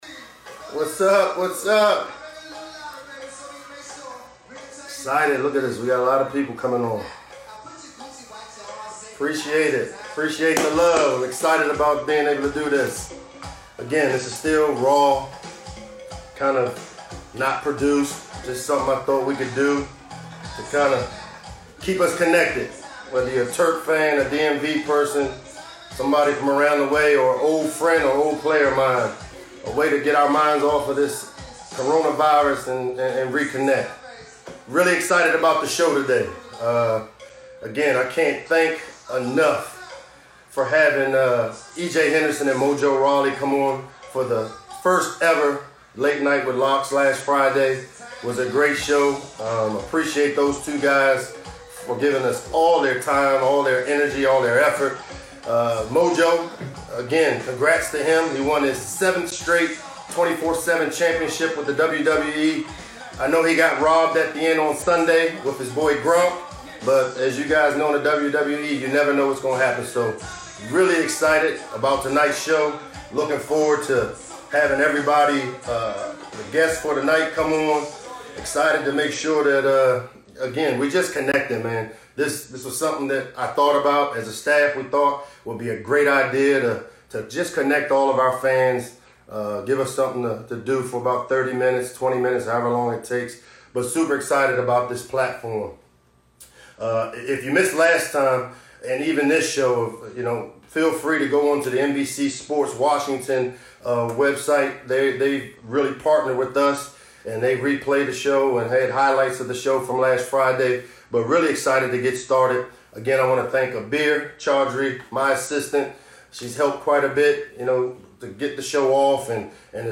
April 09, 2020 Late Night with Locks is an Instagram live show hosted by head football coach Michael Locksley every Tuesday and Friday evening at 7 p.m. This episode of Late Night with Locks features head women's basketball coach Brenda Frese and NFL defensive end Yannick Ngakoue.